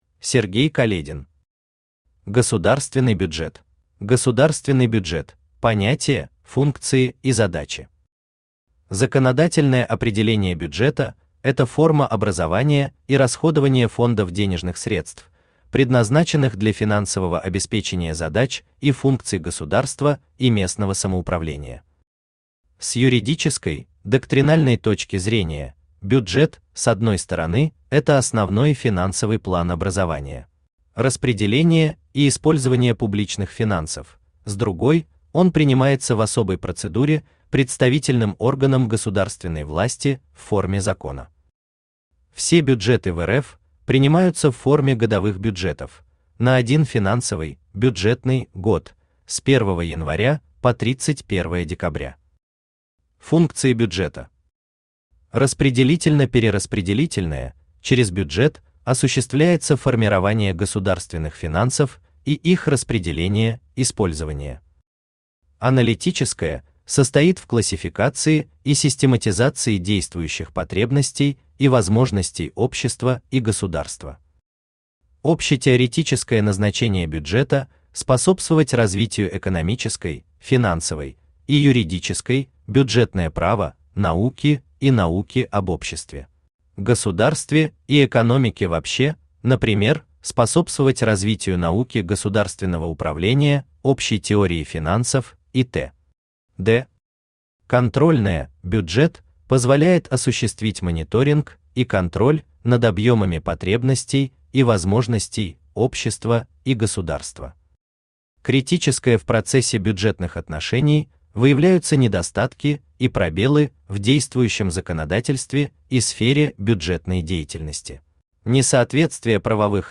Аудиокнига Государственный бюджет | Библиотека аудиокниг
Aудиокнига Государственный бюджет Автор Сергей Каледин Читает аудиокнигу Авточтец ЛитРес.